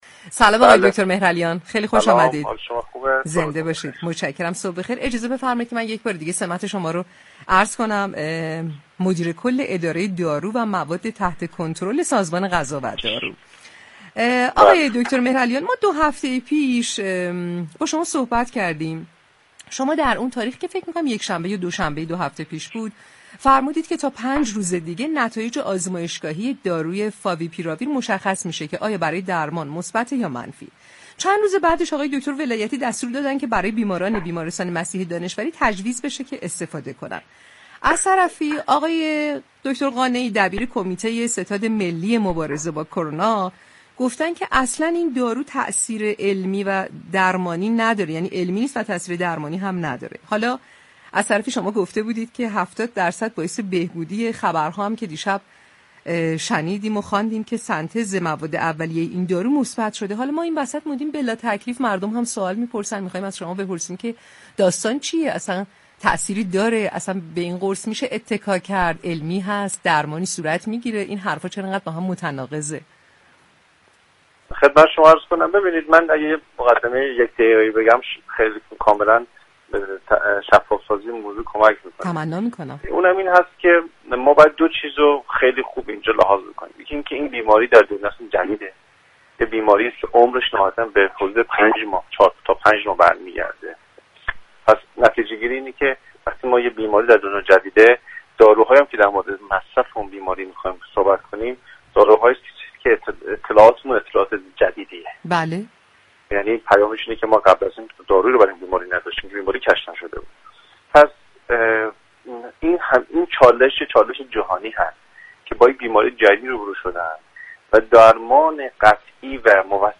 غلامحسین مهرعلیان مدیركل اداره دارو و مواد تحت كنترل سازمان غذا و دارو در برنامه "تهران كلینیك" شنبه 30 فروردین رادیو تهران در رابطه با داروی "فاویپیراویر" و تاثیر آن گفت: بیماری كووید ١٩ یك بیماری جدید است، در نتیجه داروهایی كه برای این بیماری جدید باید مصرف شود هم ناشناخته و جدید است چون برای این بیماری قبلا دارویی كشف نشده و در جهان هم هیچ درمان قطعی و موثقی برای این بیماری پیدا نشده است و این اخبار ضدونقیض در مورد داروی "فاویپیراویر" و دیگر داروها كه مى شنویم به همین علت است.